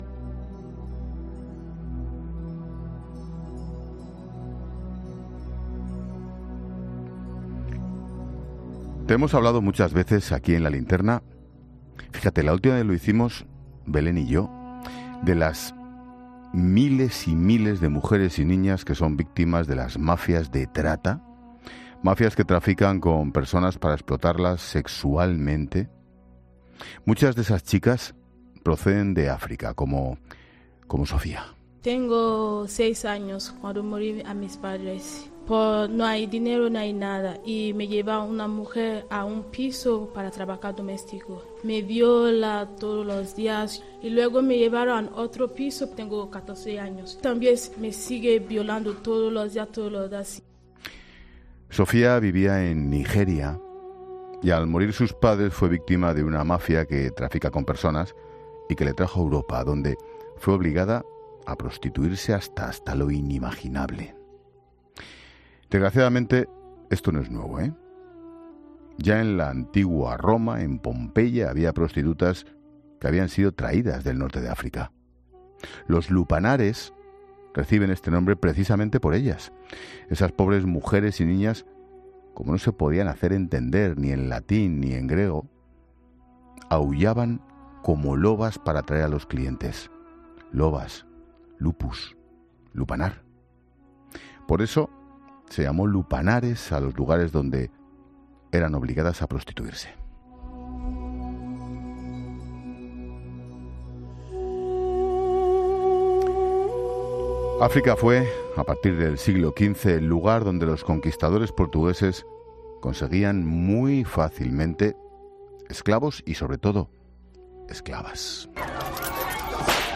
Hablamos con Veronique Olmi, la autora de la biografía e "Bakhita", la mujer que salió de la explotación sexual para ser en un símbolo del...